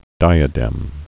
(dīə-dĕm, -dəm)